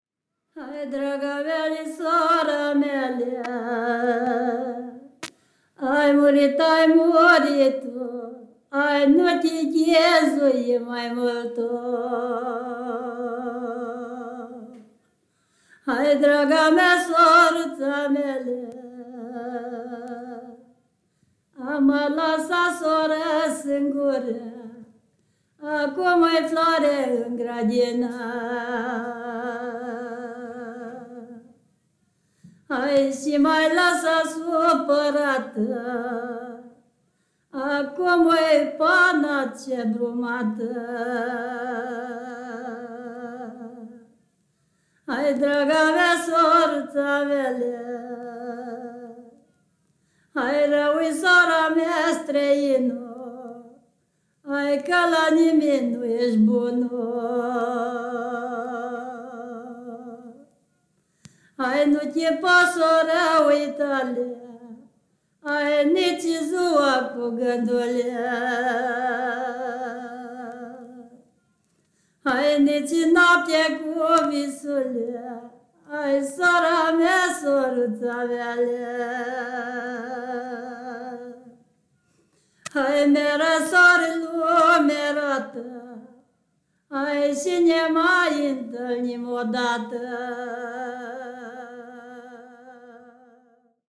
フランス・OCORAからルーマニア民族音楽です。